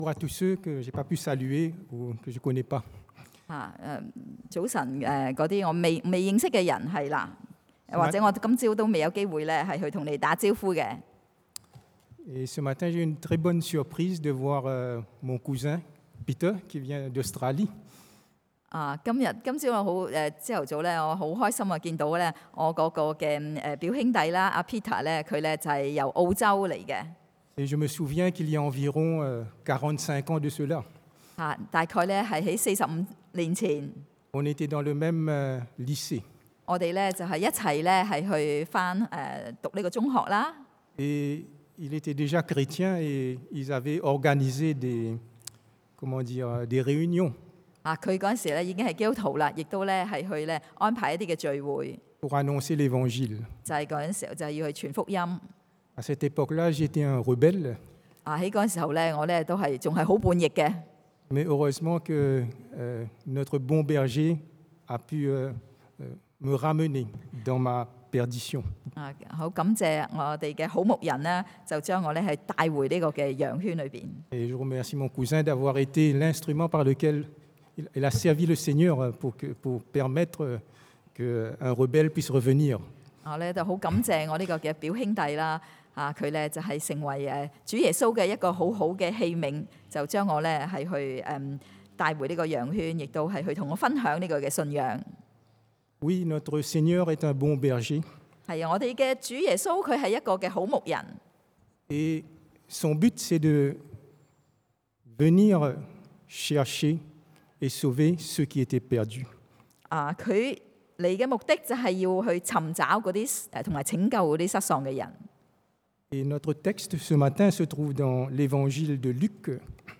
Passage: Luc 路加福音15:1-10 Type De Service: Predication du dimanche